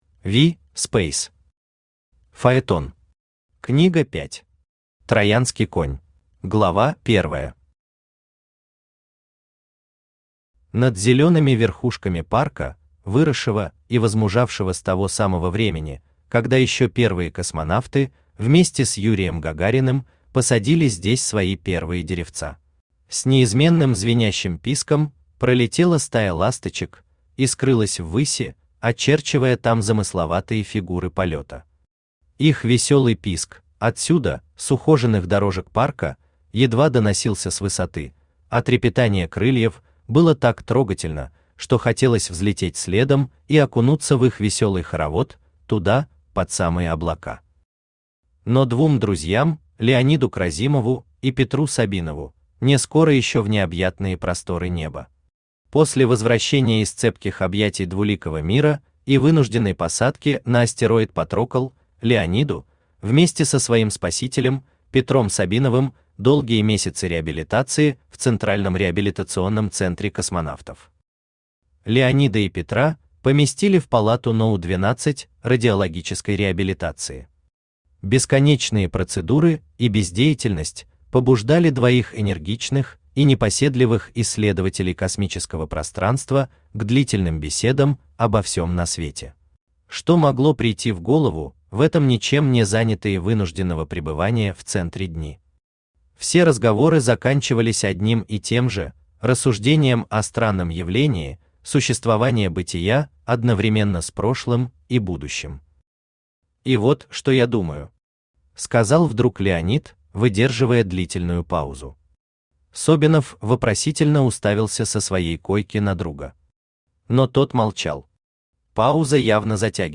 Аудиокнига Фаетон. Книга 5. Троянский конь | Библиотека аудиокниг
Троянский конь Автор V. Speys Читает аудиокнигу Авточтец ЛитРес.